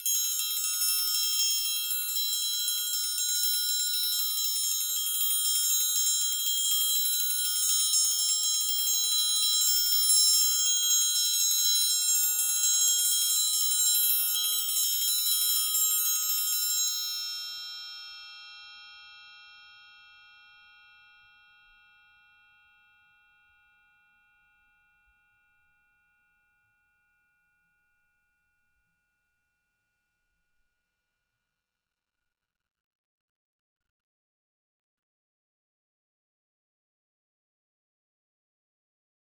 Triangle3-Roll_v2_rr1_Sum.wav